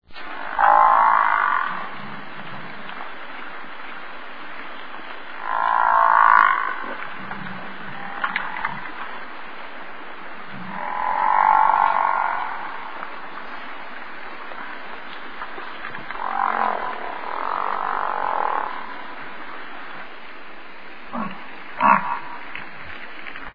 bizon-bison-bison.mp3